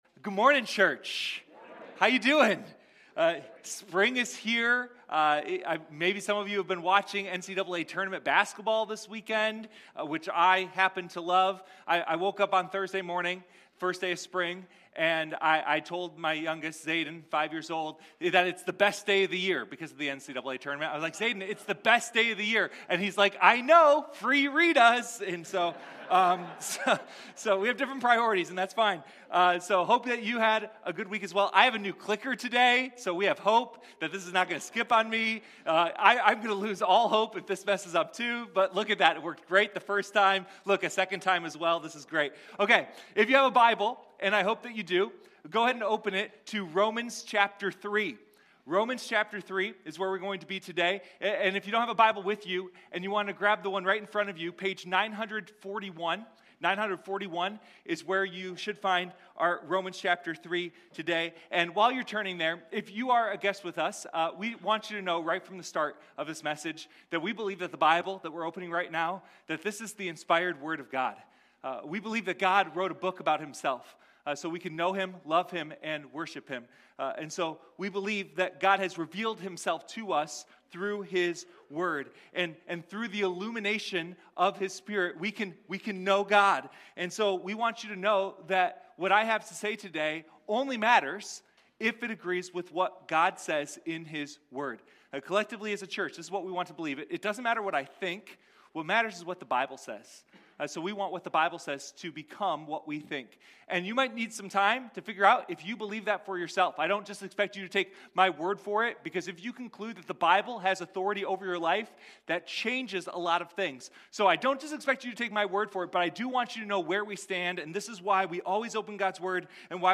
Sunday Morning The Romans Road